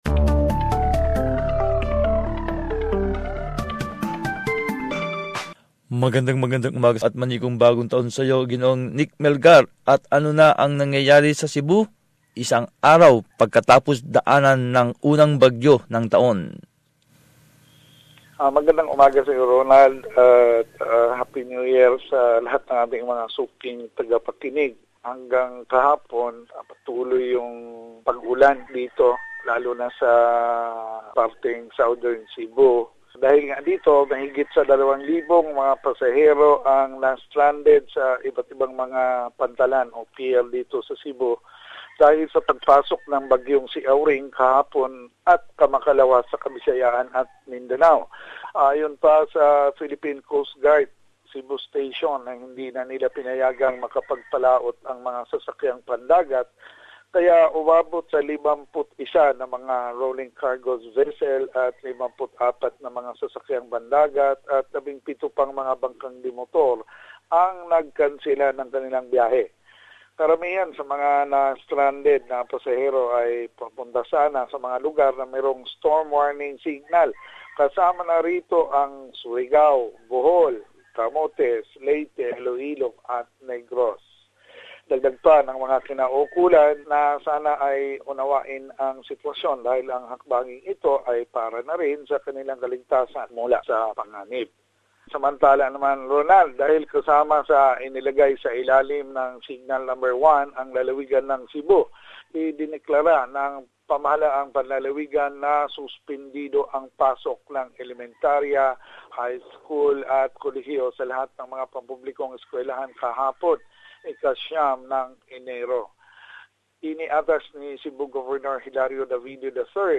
Balitang Bisayas. Summary of latest news from the region